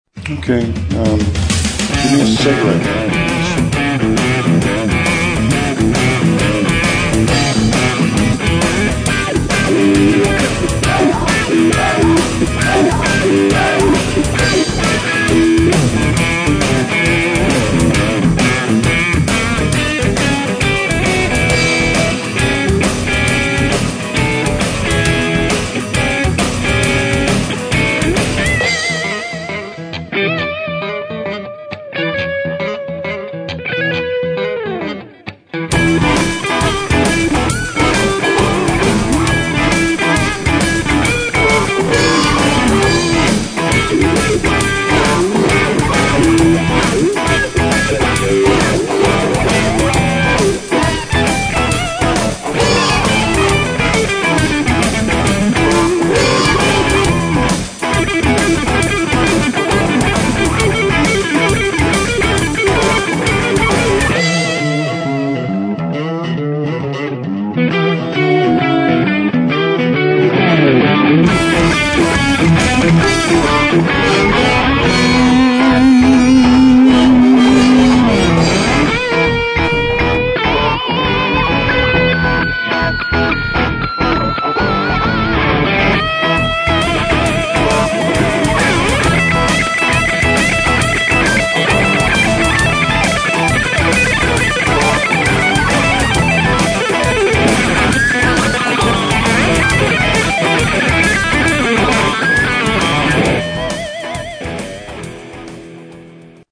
-     Instrument : Guitares lead et rythmique.
Le principe : tous les participants téléchargent une back-track commune, enregistrent un solo dessus et postent le mix sur le site.